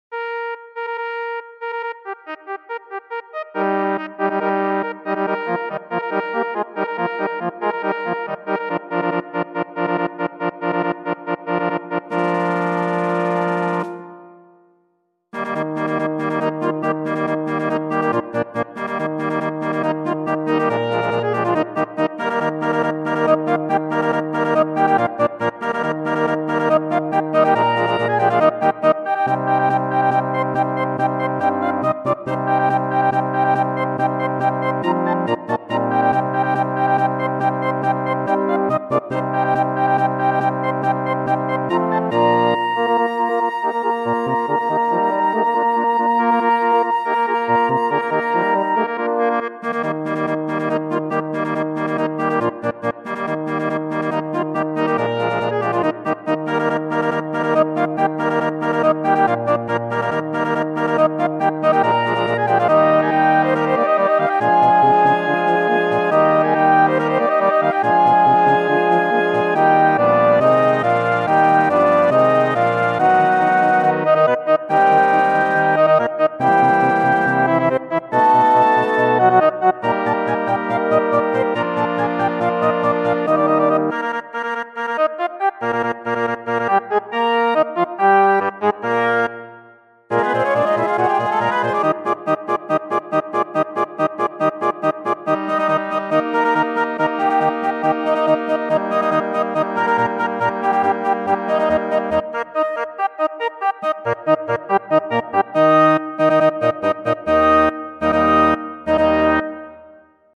Gattung: Jugendmusik
Besetzung: Blasorchester